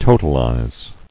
[to·tal·ize]